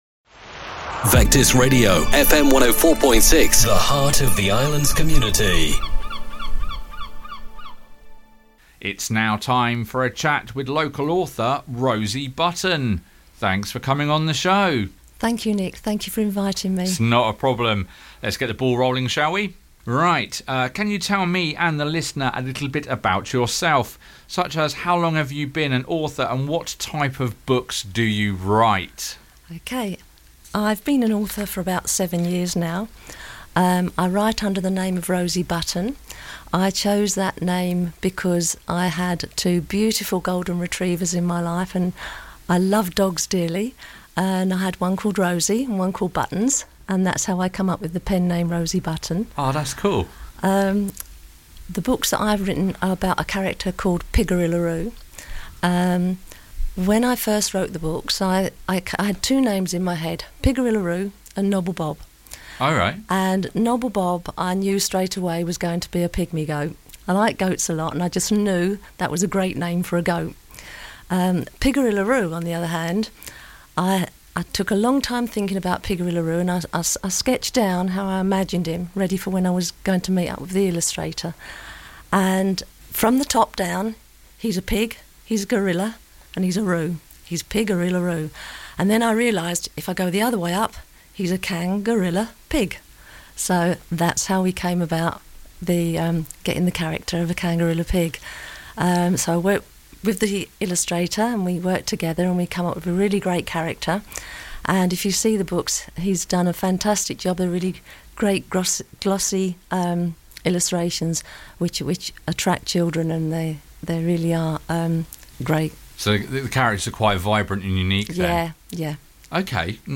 If you missed the original broadcast here is the podcasted version of the chat